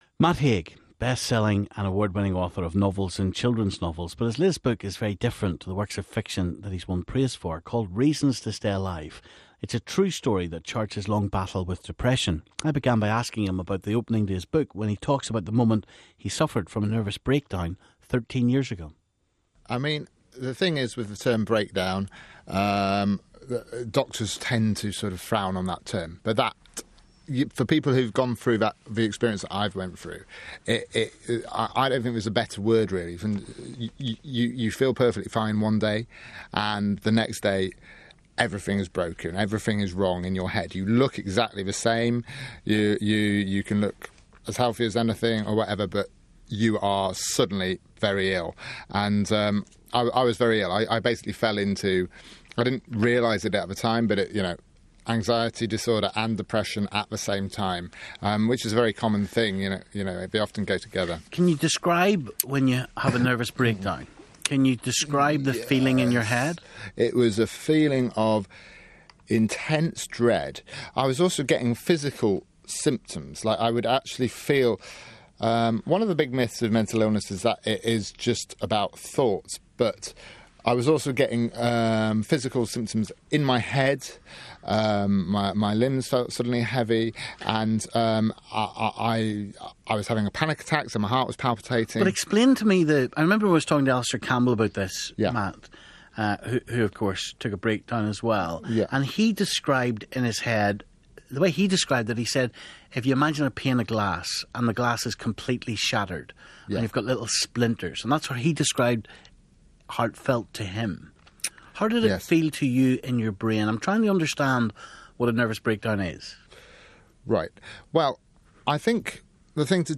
Big Friday Interview: Matt Haig